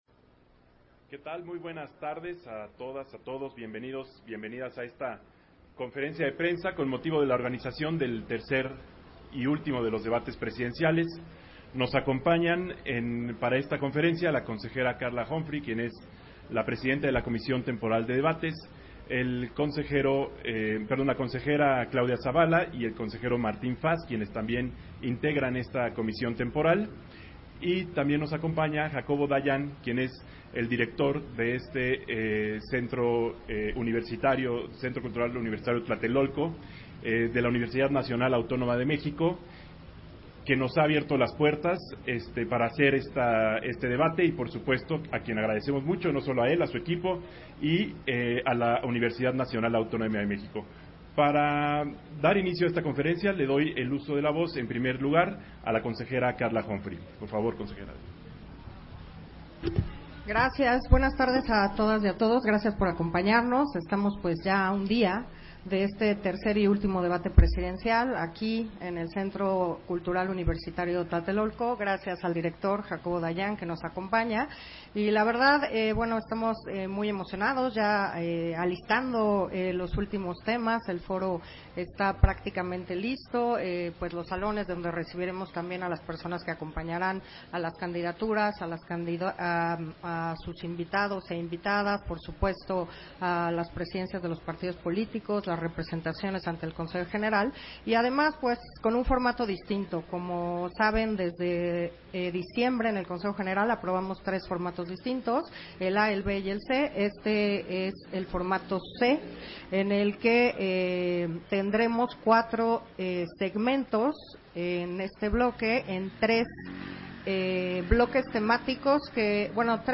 Conferencia de prensa y presentación del set para el Tercer Debate Presidencial 2024